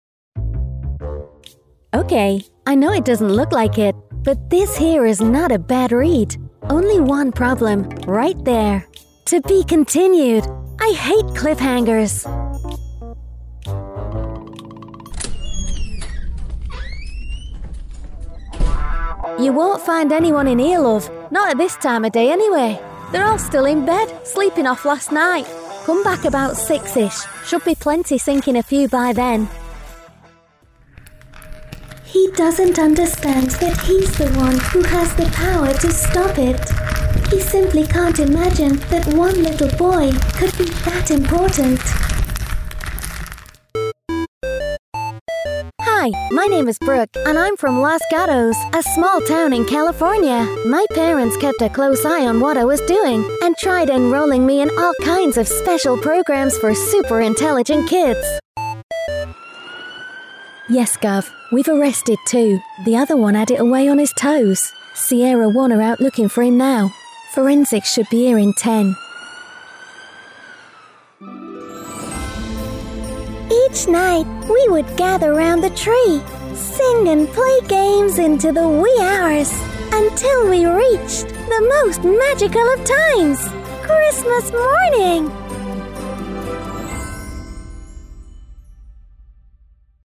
Working from my own broadcast quality studio, I provide voiceovers for all kinds of clients from Independent Organisations to Global Brands.
I can read with a neutral UK accent or with one of my various character voices and regional accents, including authentic Northern dialects of Manchester, Liverpool, Cheshire and Yorkshire, as well as more off the wall characters for those magical, memorable reads!
Sprechprobe: Industrie (Muttersprache):
My voice can be described as authentic, sincere, assured and clear, an excellent choice for Radio & TV Commercials, Corporate and Explainer Videos, E-Learning & Training Narration, Telephone IVR and On-Hold Voiceovers, I have numerous character voices available for Commercials, Animation & Gaming too.
Gaming Animation Showreel 2023.mp3